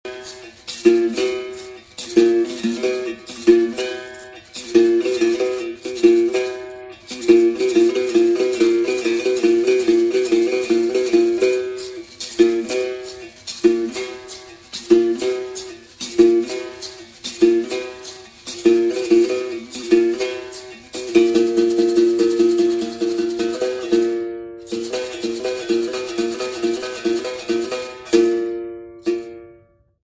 Vários instrumentos da roda de Capoeira Angola
O instrumento é composto pela verga de biriba, corda de aço, cabaça raspada, courão e caro.
É tocado com a baqueta e o dobrão (uma peça de metal, antigamente uma moeda), com acompanhamento do caxixi.